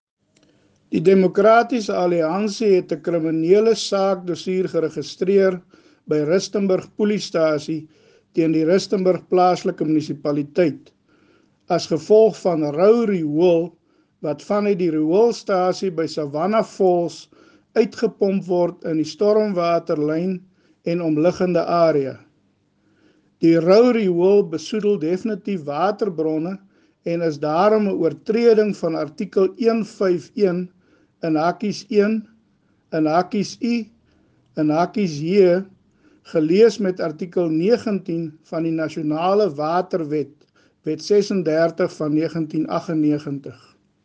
Note to Editors: Please find the attached soundbite in
Afrikaans by Cllr Gert Du Plessis.